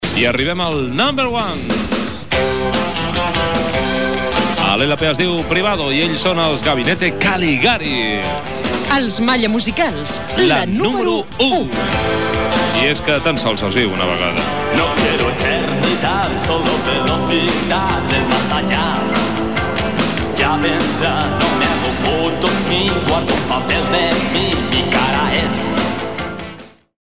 Identificació del programa i el tema musical número ú de la llista aquella setmana
Musical